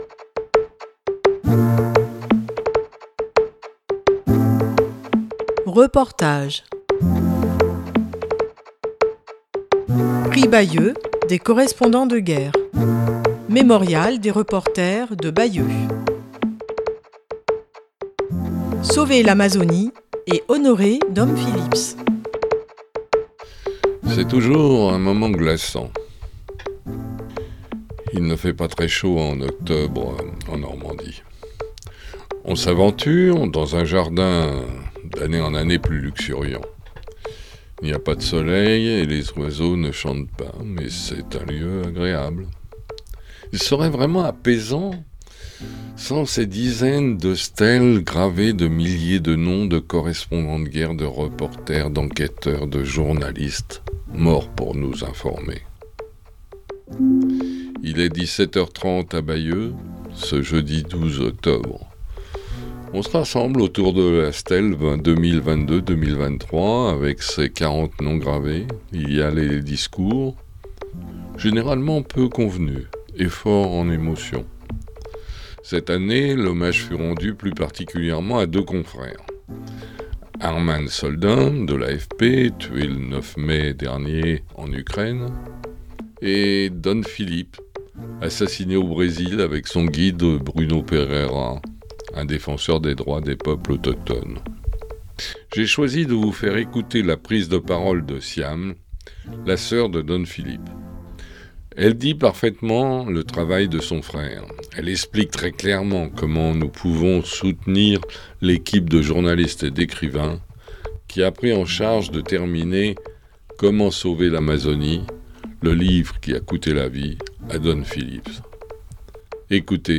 Reportage
Il est 17 h.30, à Bayeux, ce jeudi 12 octobre, on se rassemble autour de la stèle 2022-2023, avec ses quarante noms gravés. Il y a les discours, généralement peu convenus et fort en émotion.